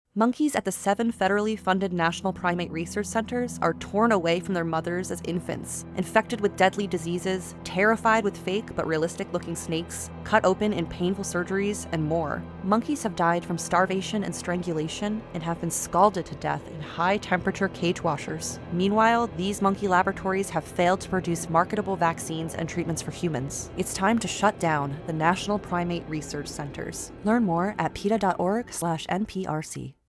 Instructions for Downloading This Radio PSA Audio File
nprc_cruelty_radio_ad_petaus_v1.mp3